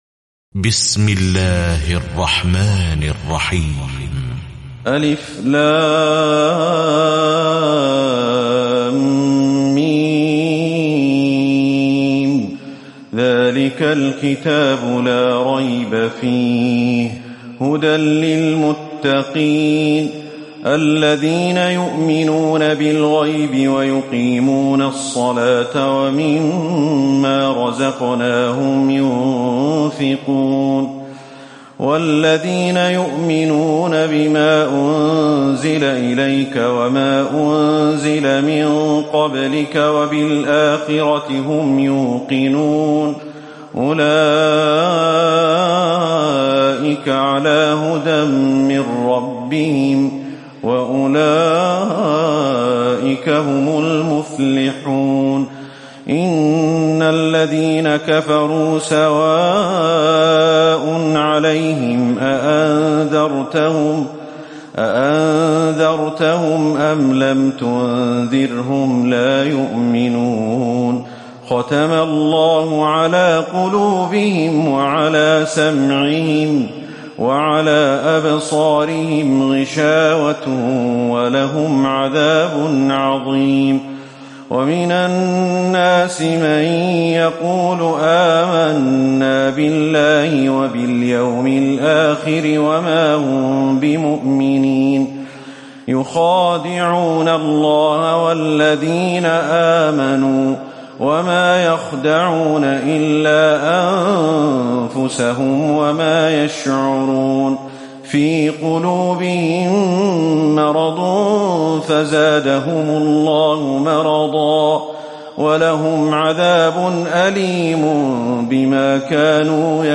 تراويح الليلة الأولى رمضان 1438هـ من سورة البقرة (1-77) Taraweeh 1st night Ramadan 1438H from Surah Al-Baqara > تراويح الحرم النبوي عام 1438 🕌 > التراويح - تلاوات الحرمين